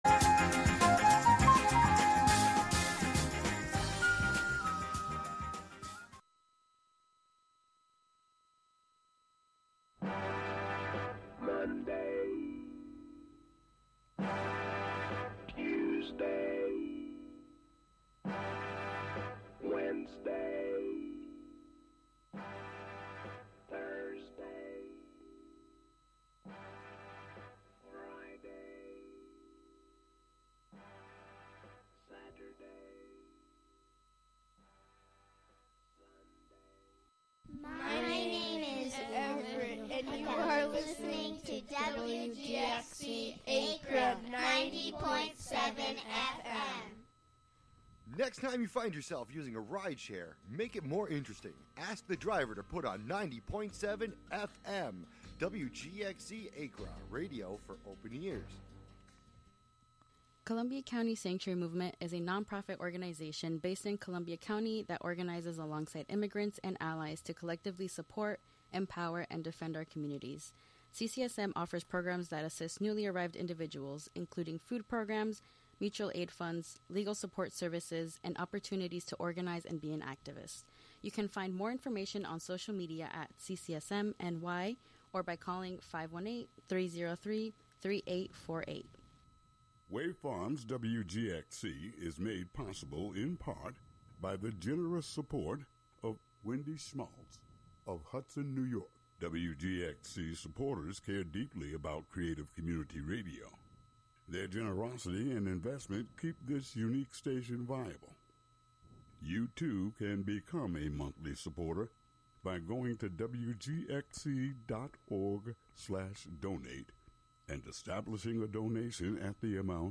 In this show, we explore the intersections of fermentation with revolt, rebellion and transformation. Mold, yeast and bacteria will be the thread as we experience different points of historical contact between fermentation and collective revolution through songs, poems, sound art excerpts, and audio recordings.